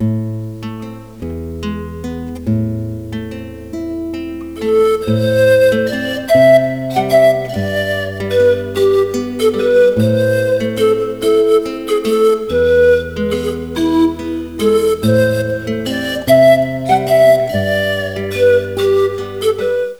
This instrumental CD features 18 popular Christmas melodies.